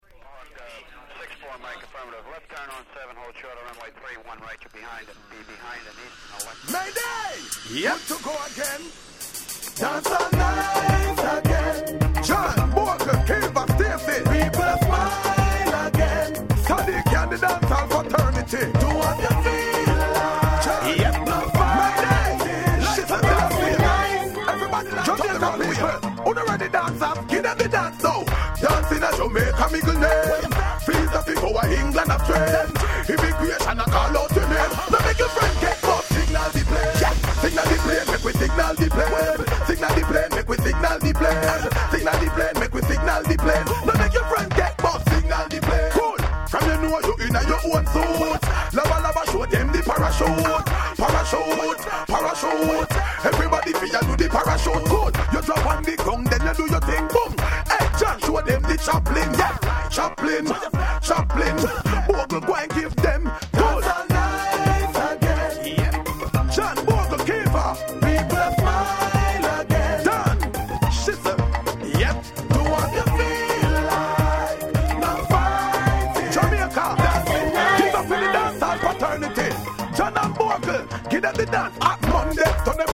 03' Super Hit Dancehall Reggae !!